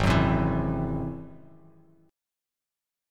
G#+7 chord